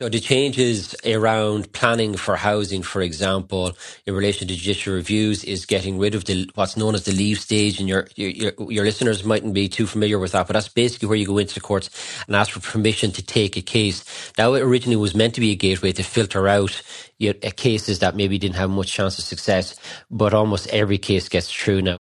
Minister James Browne says judicial reviews are slowing down the process…………….